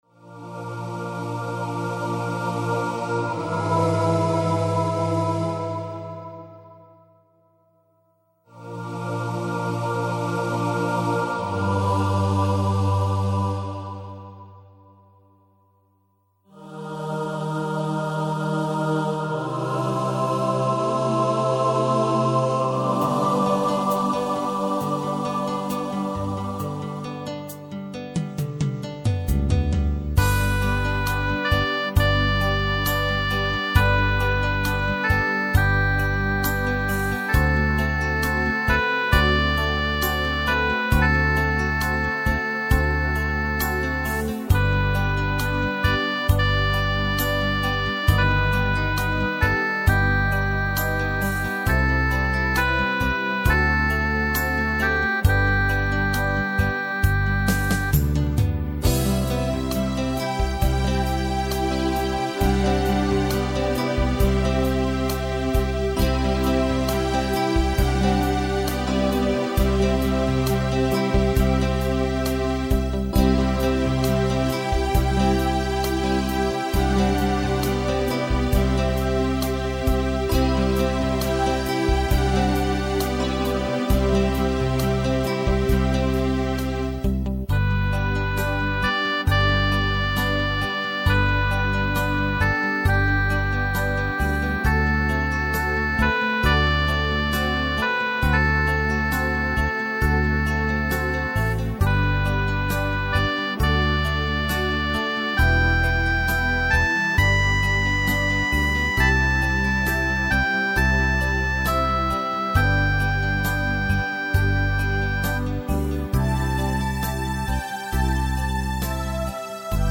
Nr. 5 Largo (03:39 (Keyboard und Synthesizer)